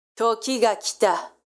ボイス
ダウンロード 女性_「時が来た」
中音女性
josei_toki_ga_kita.mp3